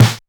Index of /musicradar/essential-drumkit-samples/DX:DMX Kit
DX Snare 03.wav